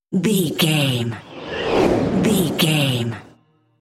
Whoosh airy
Sound Effects
futuristic
whoosh
sci fi